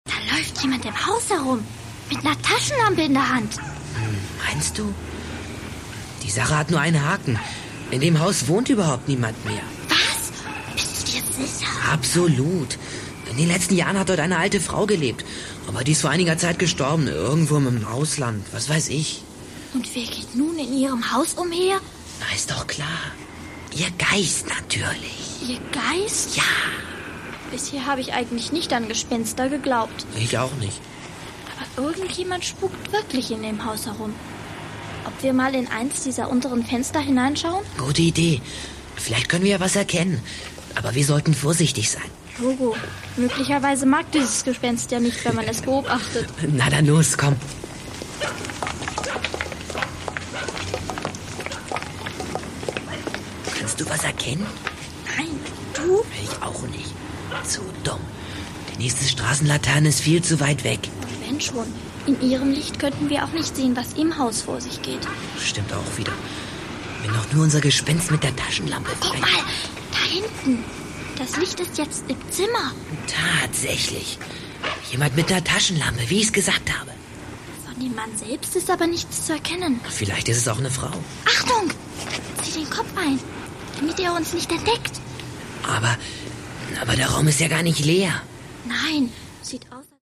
Produkttyp: Hörspiel-Download